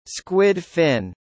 ・エンペラ（三角の部分）「squid fin」スクウィッドフィン